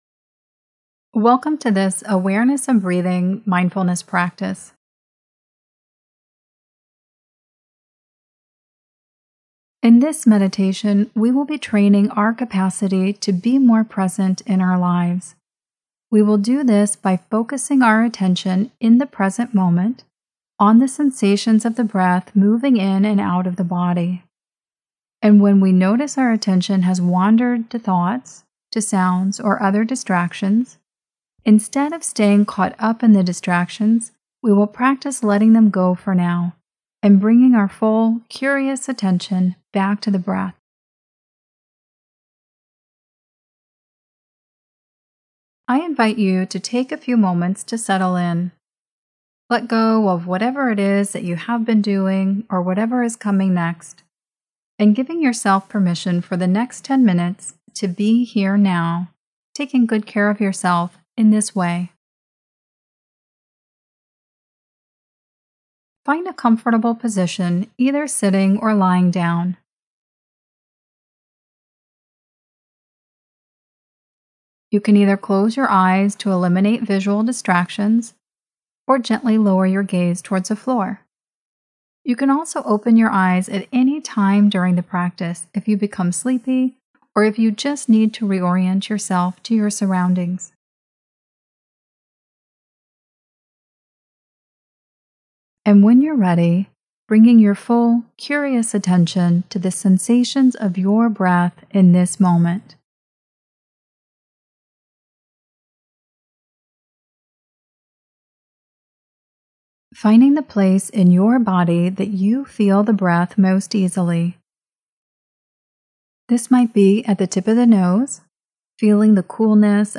Awareness of Breathing Meditation.mp3